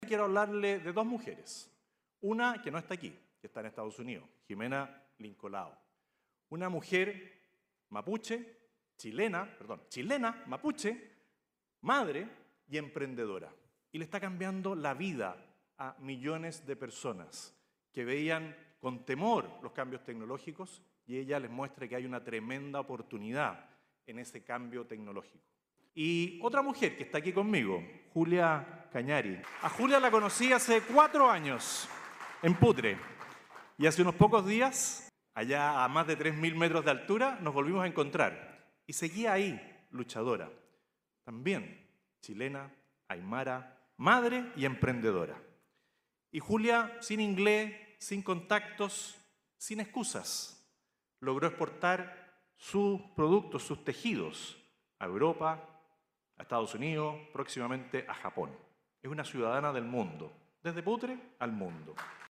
Luego, el candidato del Partido Republicano, José Antonio Kast, ejemplificó parte de sus propuestas mencionando a dos mujeres emprendedoras, una en Estados Unidos y la otra en el norte de Chile.